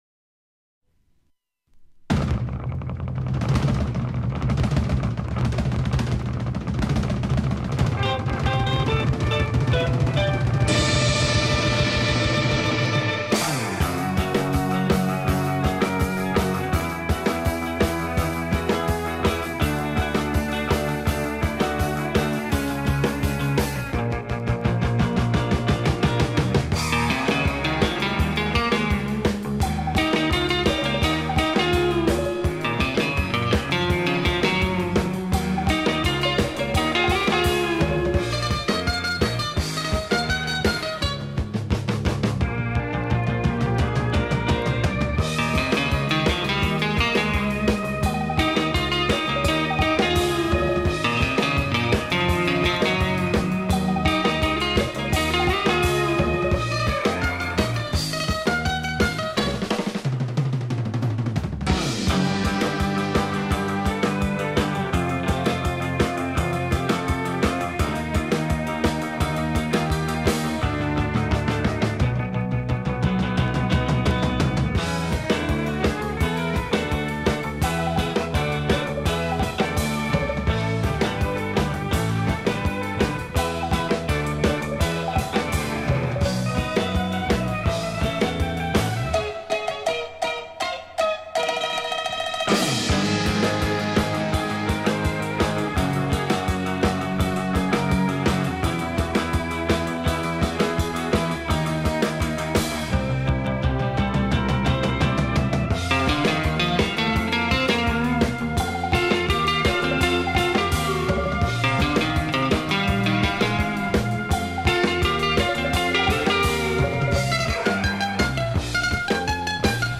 instrumental “no wave” surf rock band